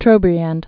(trōbrē-ănd, -änd)